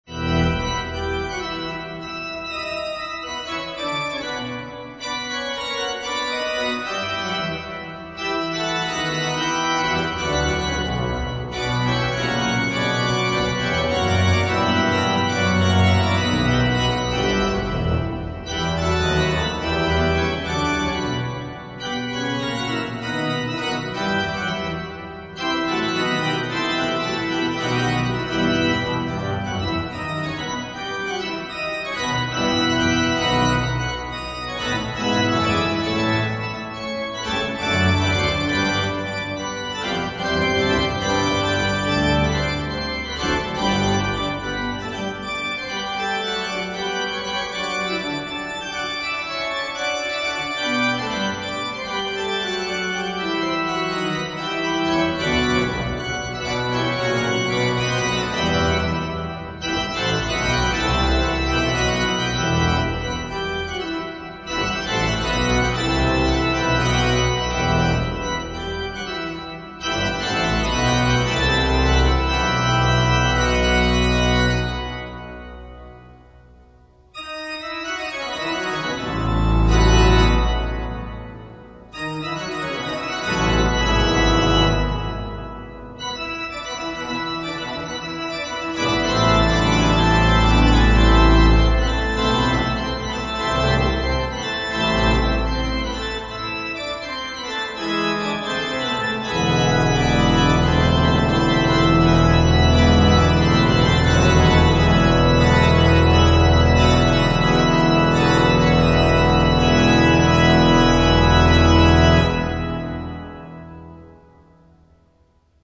L'Orgue de St Rémy de Provence